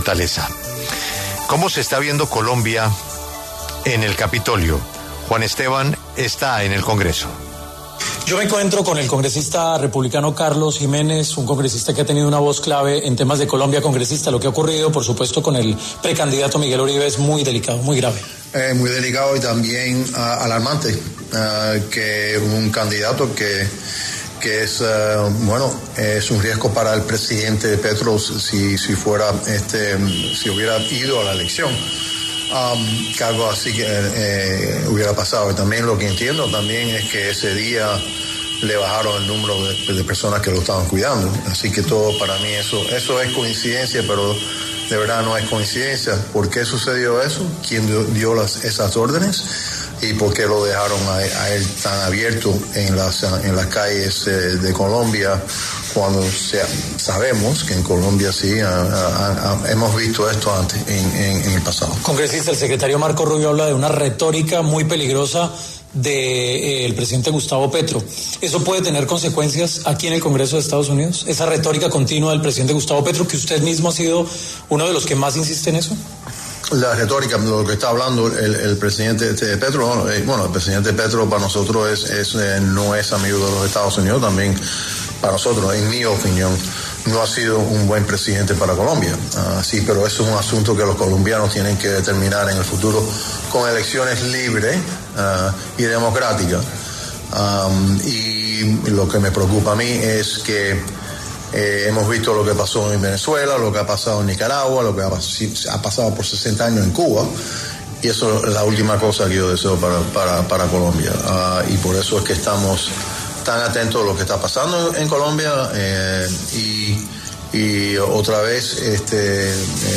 Carlos Antonio Giménez, congresista republicano, pasó por los micrófonos de La W y habló sobre el atentado al senador colombiano Miguel Uribe, la imagen de Gustavo Petro en Estados Unidos y también se refirió a las jornadas de manifestaciones en California.